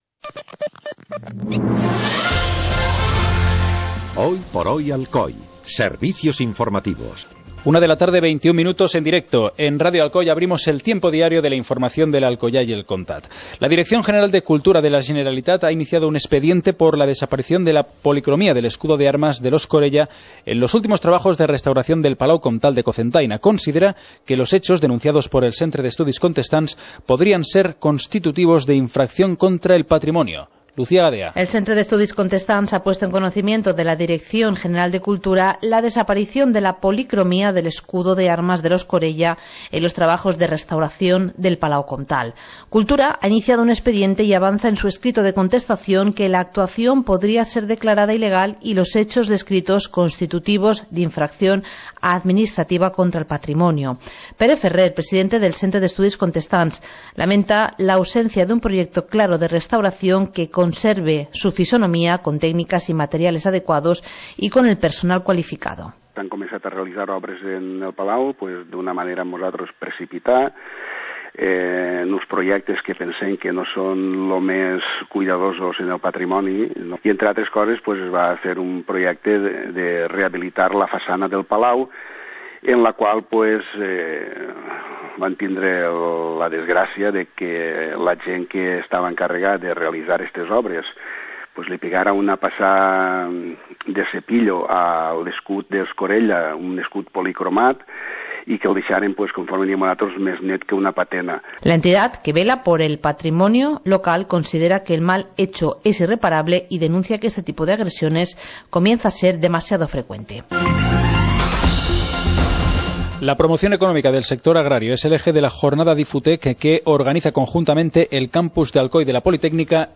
Informativo comarcal - jueves, 16 de abril de 2015